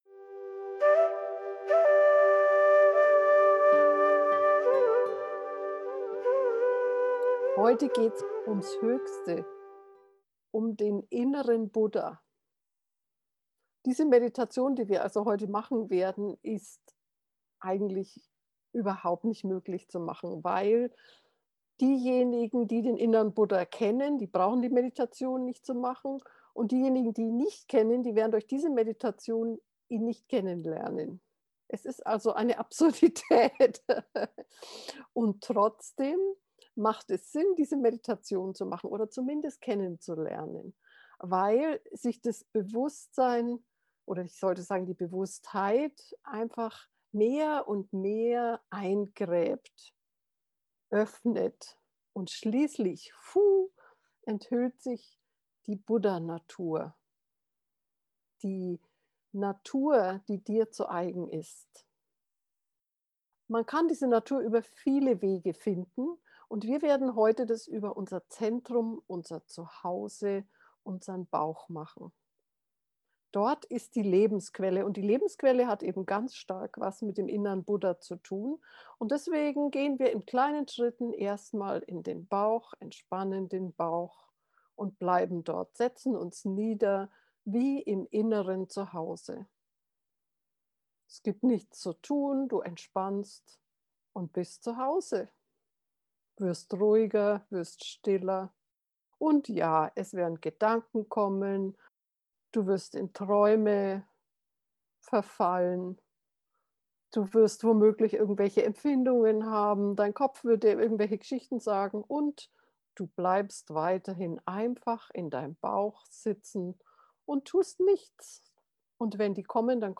bauch-buddha-natur-gefuehrte-meditation